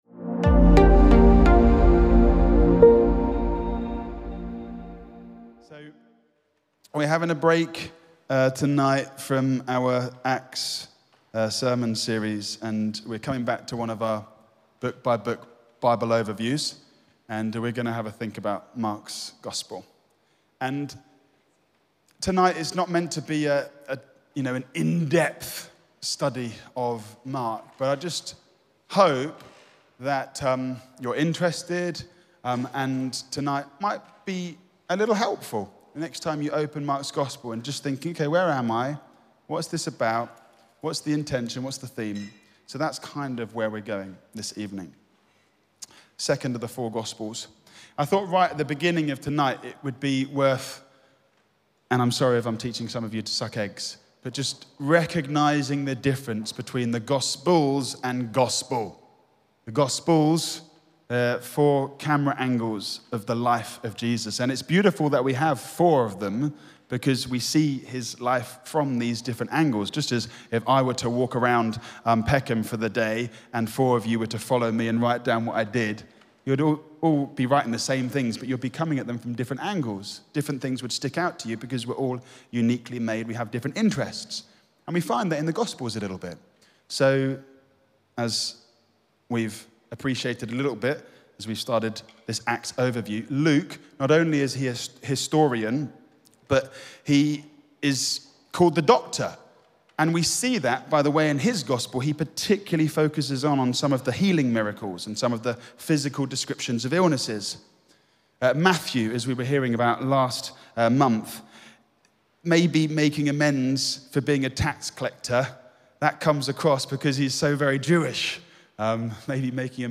The Evening Sermon 01.02.26 - All Saints Peckham
Audio Sermon